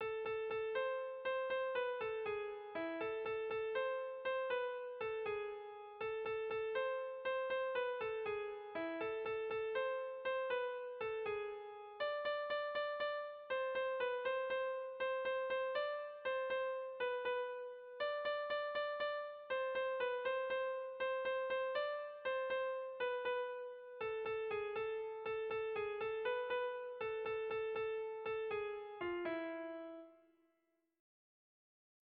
Tragikoa
Hamarreko handia (hg) / Bost puntuko handia (ip)
A-A-B-B-C